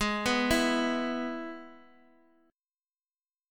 G#m#5 chord